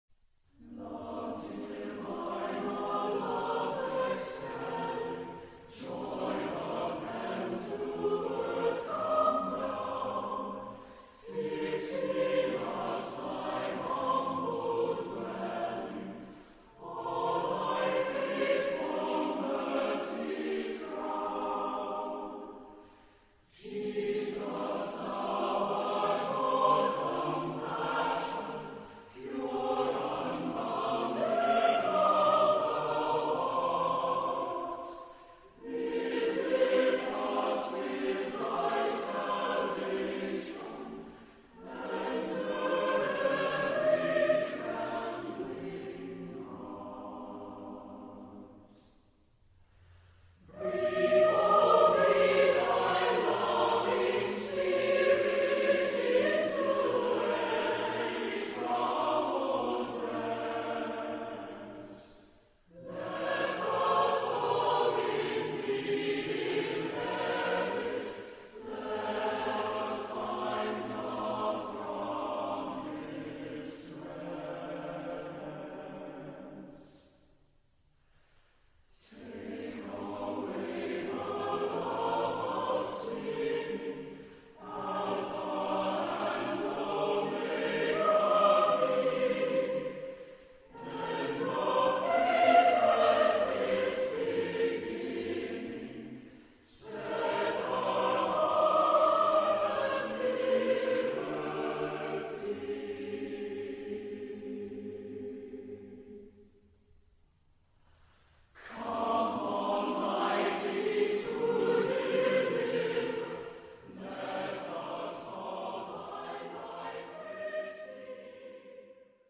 Genre-Style-Form: Sacred ; Hymn (sacred)
Mood of the piece: prayerful
Type of Choir: SATB  (4 mixed voices )
Tonality: A flat major